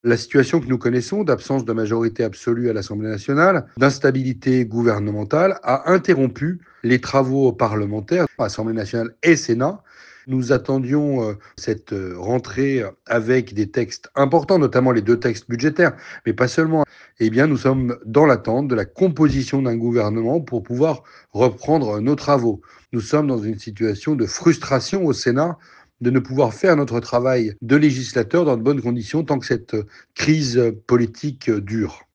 Depuis les dernières semaines, le gouvernement démissionnaire traitait les affaires courantes mais aucune nouvelle proposition de loi n’a pu être débattue et les sujets n’avancent pas comme le déplore le sénateur (Union centriste) de Haute-Savoie Loïc Hervé.